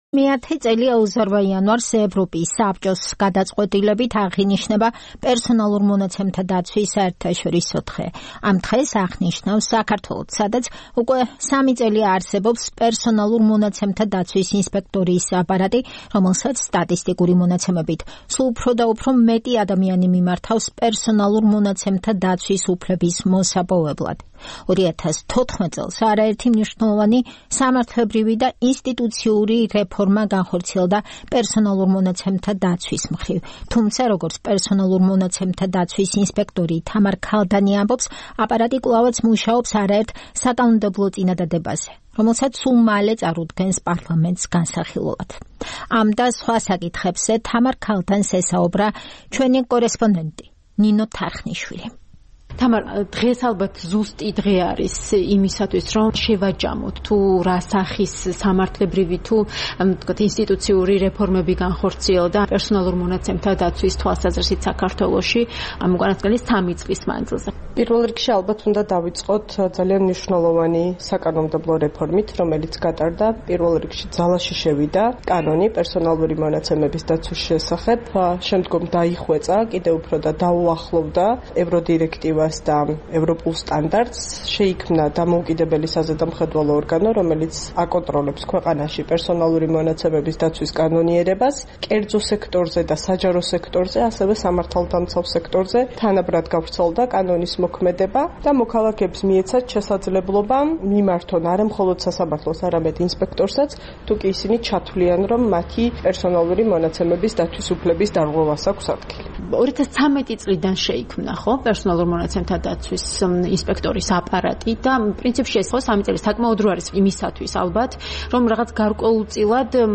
ინტერვიუ